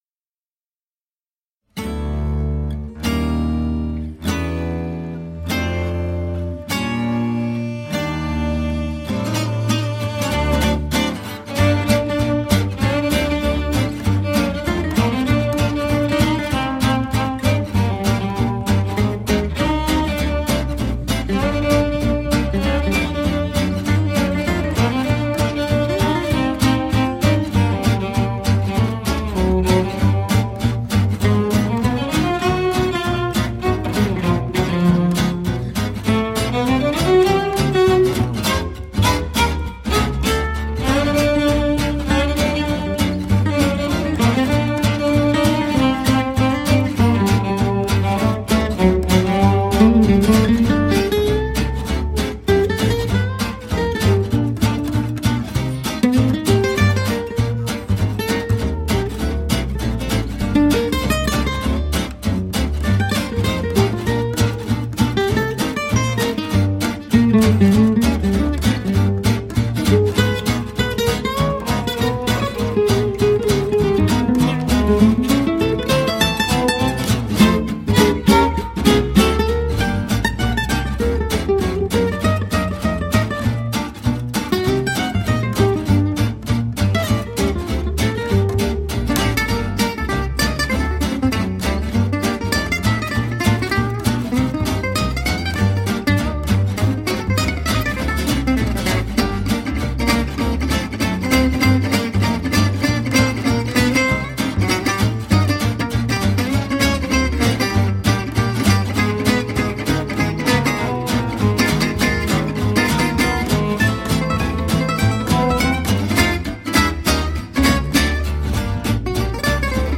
gypsy-jazz (o swing manouche)
chitarra, chitarra, contrabbasso